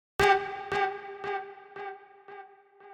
Category: Samsung Ringtones